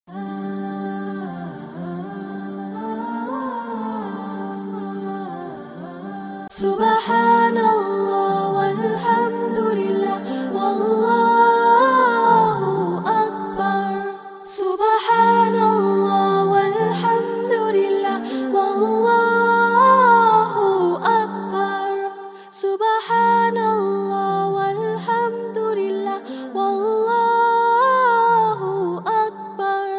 islamic ringtone